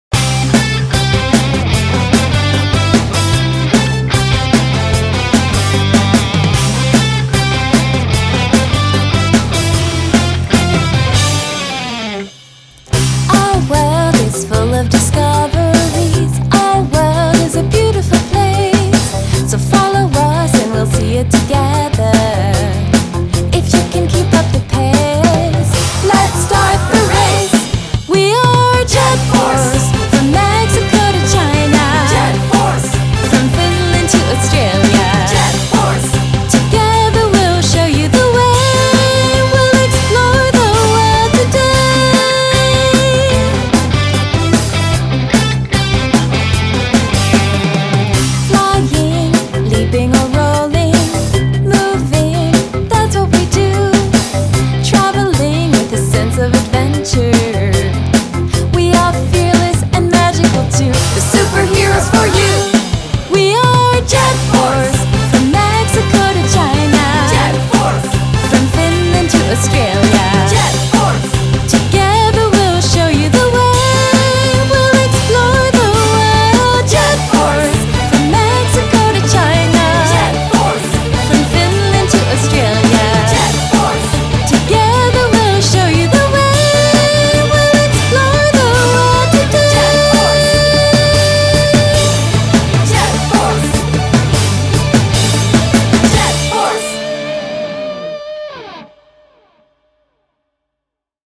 style: rock/pop